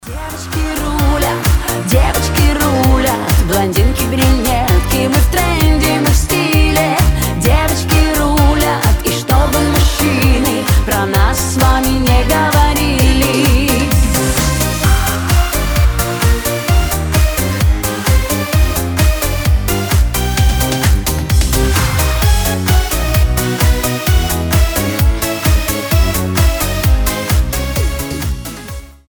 • Качество: 320, Stereo
позитивные
веселые
женские